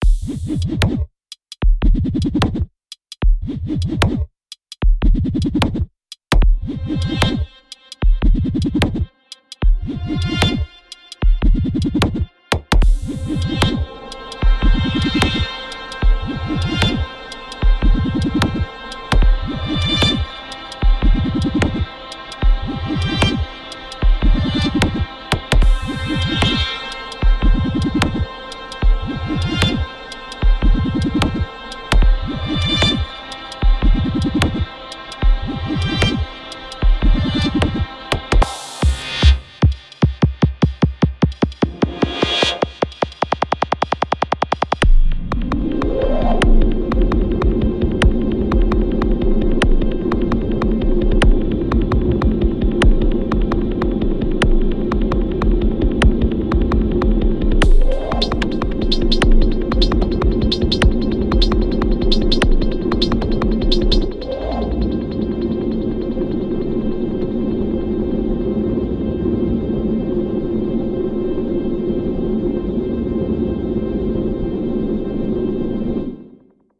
Here’s the Alloy track, same tricks as with the swarm, then mindless tweaking with ctrl all, until it sounds different;))
Maybe it’s key to share that I have the FX track on HP with res on, to bring in low end (probably excessive)